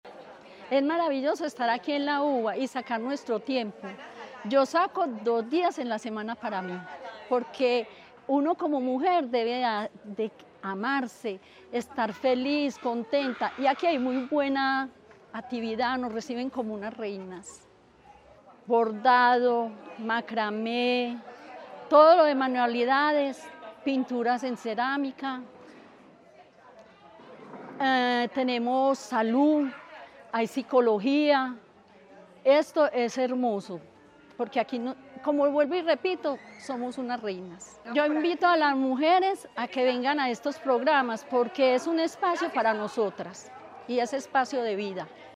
Declaraciones de beneficiaria de Círculos de Cuidado